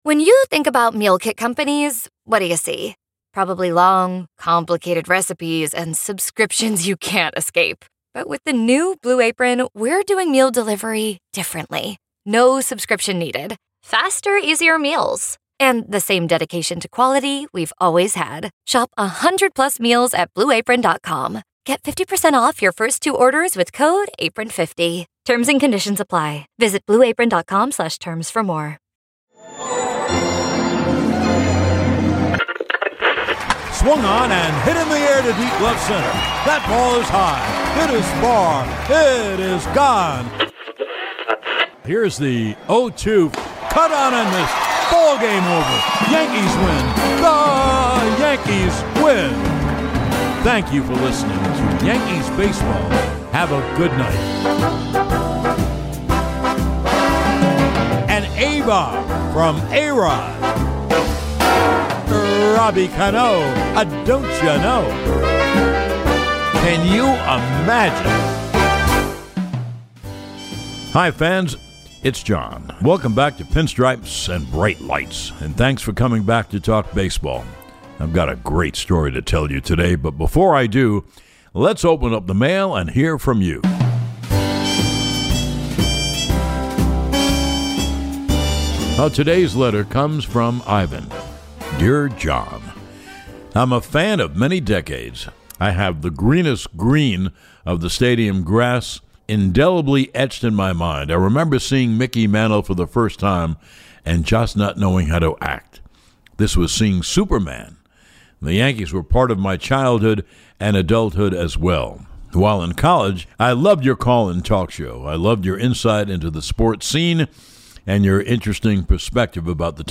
But he was almost laughed out of baseball, before Joe DiMaggio’s dramatic return in the summer of 1949. John Sterling tells you the tale and, as always, we open up the show with a letter from the fans.